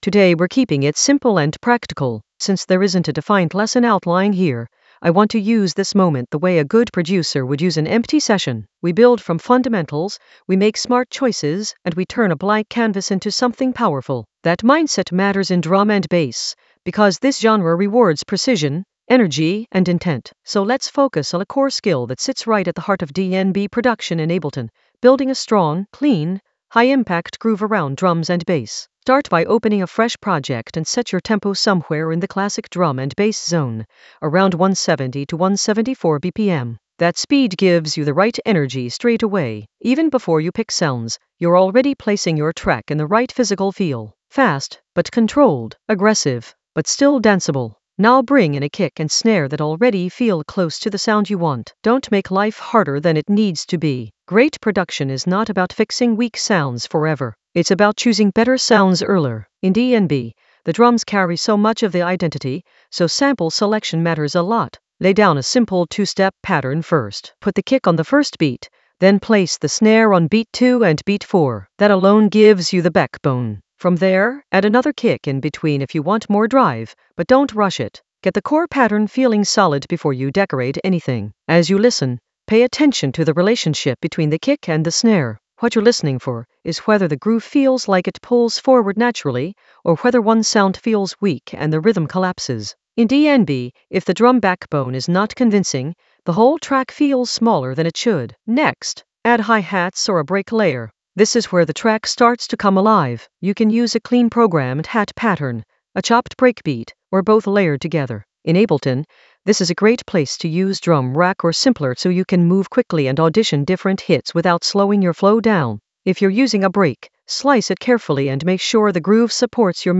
An AI-generated beginner Ableton lesson focused on Top Buzz sub basslines that slap and sizzle in the Basslines area of drum and bass production.
Narrated lesson audio
The voice track includes the tutorial plus extra teacher commentary.